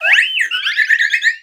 Cri de Floette dans Pokémon X et Y.